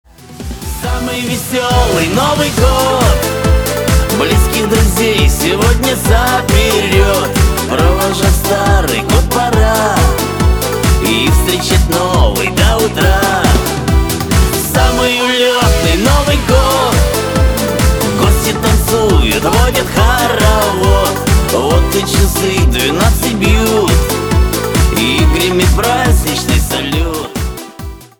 • Качество: 320, Stereo
позитивные
мужской вокал
русский шансон
праздничные